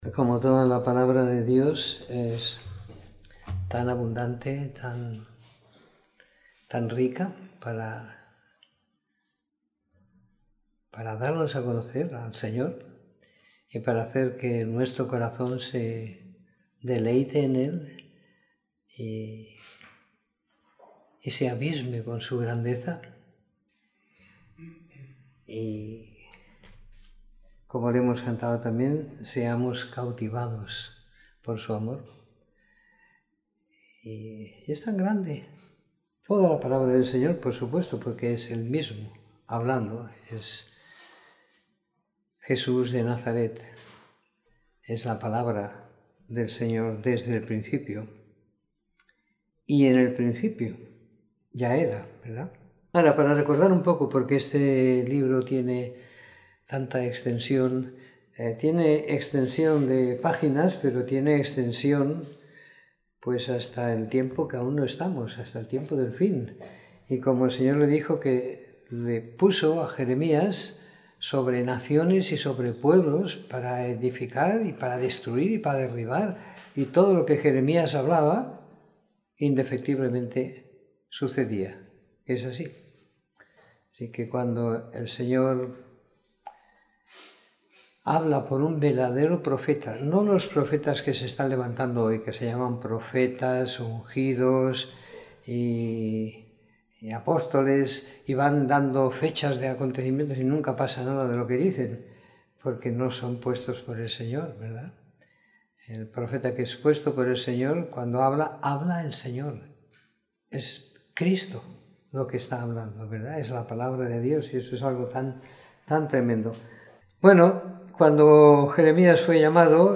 Comentario en los libros de Jeremías capítulo 31 a Lamentaciones capítulo 5 siguiendo la lectura programada para cada semana del año que tenemos en la congregación en Sant Pere de Ribes.